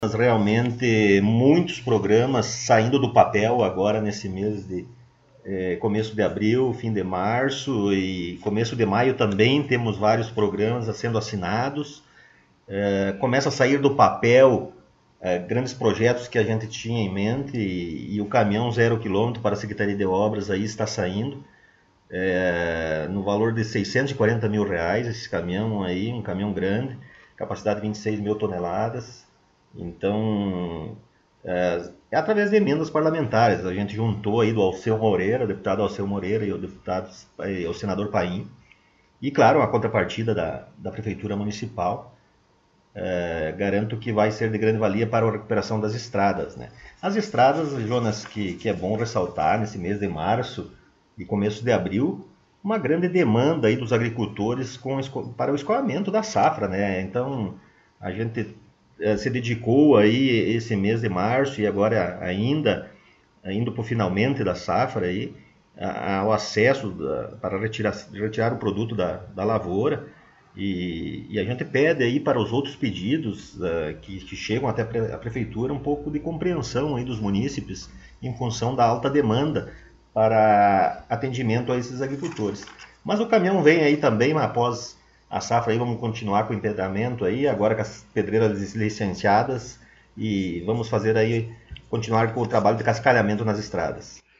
Prefeito Rodrigo Sartori concedeu entrevista